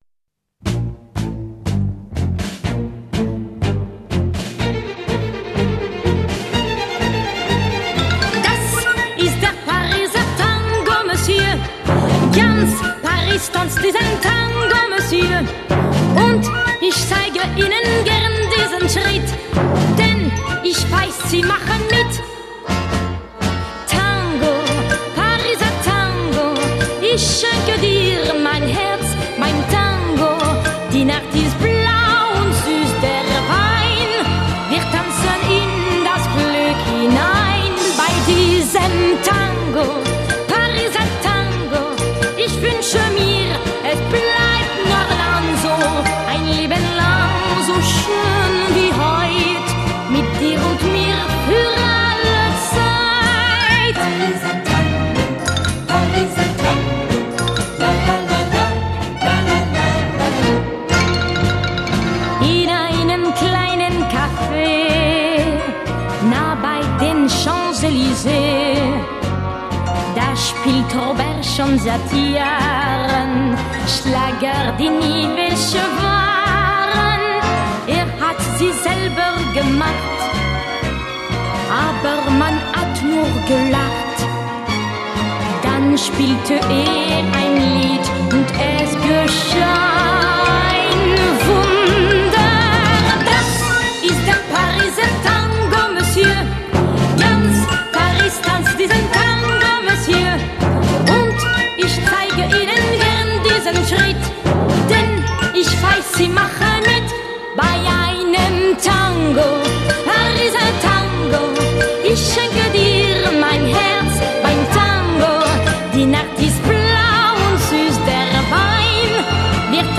Ballroom Dance